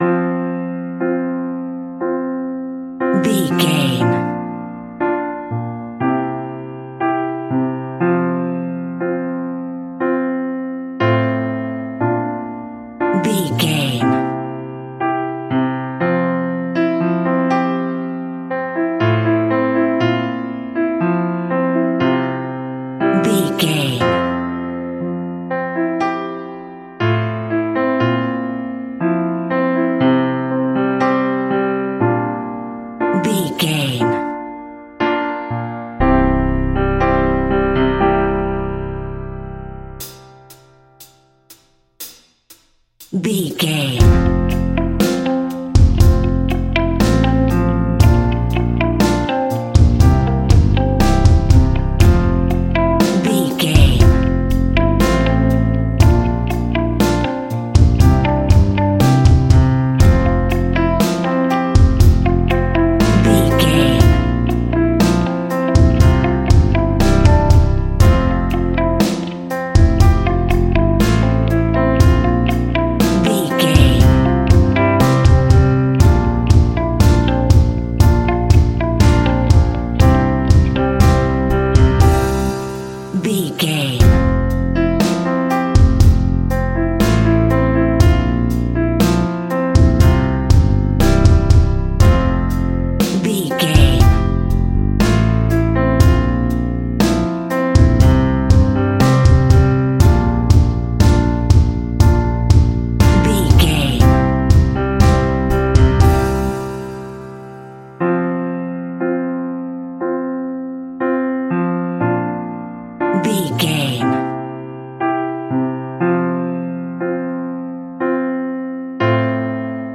Classical Piano Ballad.
Ionian/Major
romantic
drums
bass guitar
electric guitar
synthesizers